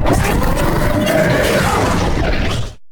taunt1.ogg